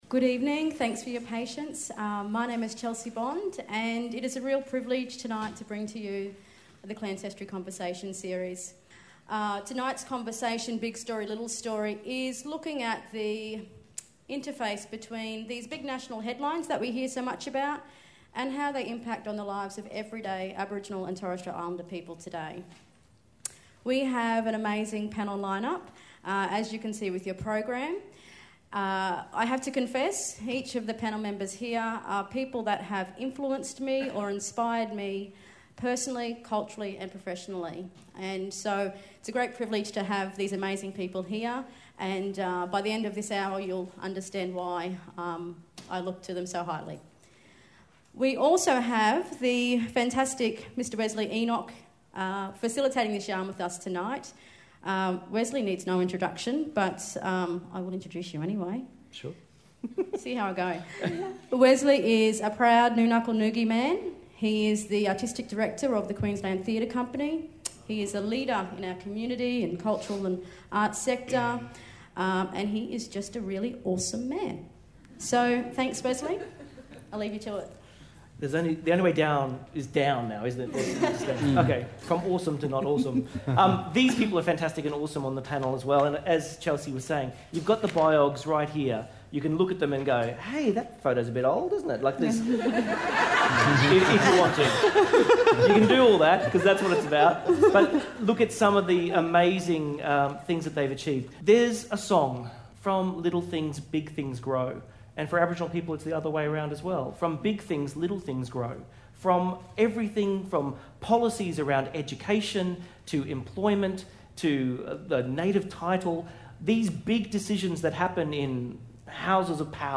Big Story, Little Story invites us to consider how Australia’s big national narratives meet the everyday life experiences of Indigenous Australians. Hosted by Wesley Enoch , our eclectic panel share with us their personal reflections to illuminate the stories underneath these national narratives.